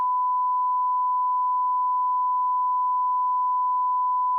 这是一个正弦音调的 PDM 录音示例。
为了测试这一点、我使用了 EVM 和具有 PDM 发生器的 APx555台式分析仪。 波形在 Audacity 下记录。